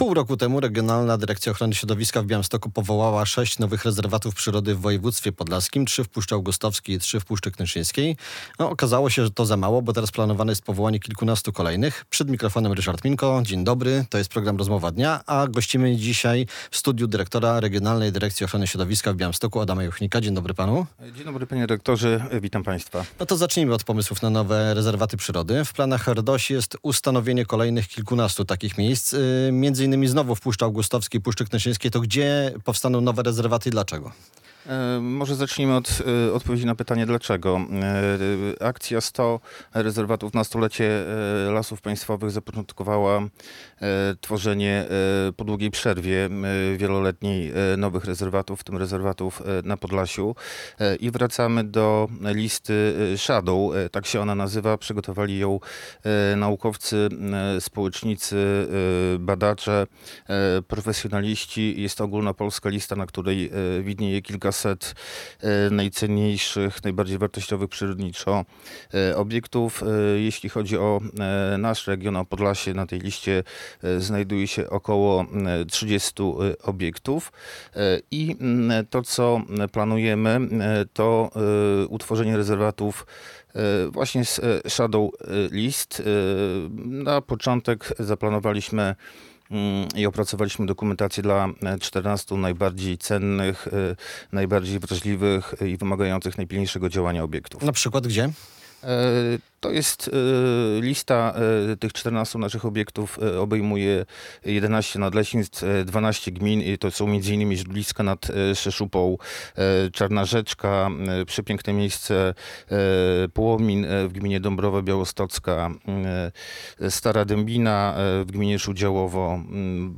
Radio Białystok | Gość | Adam Juchnik - dyrektor RDOŚ w Białymstoku